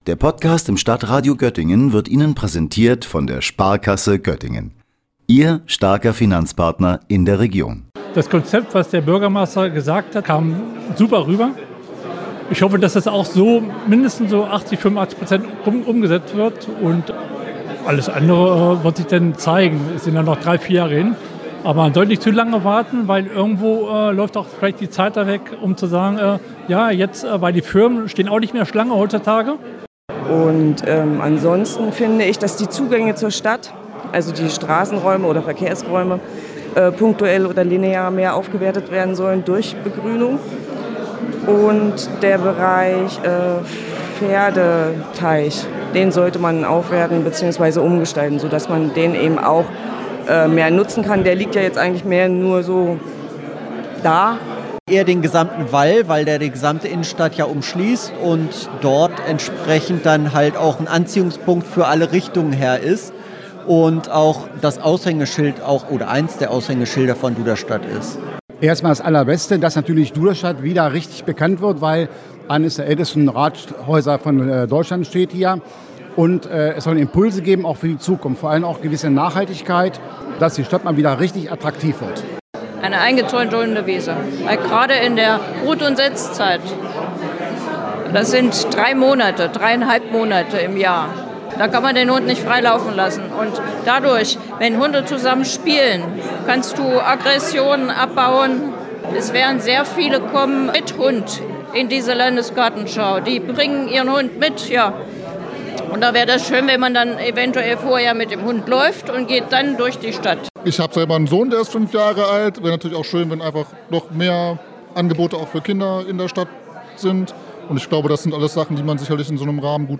Beiträge > Umfrage: Was wünschen sich Duderstädter für die Landesgartenschau 2030?
Was soll Duderstadt für die Landesgartenschau alles verändern, anpassen oder beibehalten? Das war Thema beim Ideenkreisel – einer Bürgerbeteiligung in der Duderstädter Stadtwerkstatt.